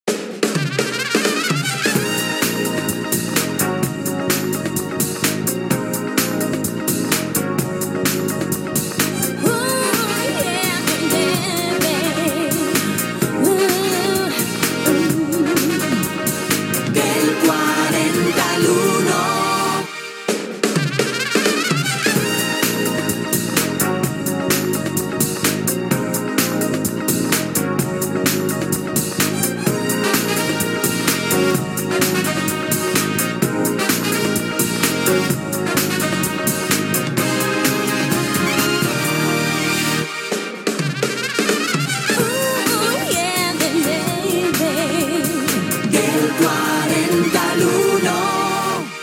Identificació cantada del programa
FM